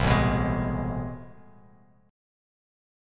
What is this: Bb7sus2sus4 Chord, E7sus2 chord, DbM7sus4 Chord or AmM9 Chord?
AmM9 Chord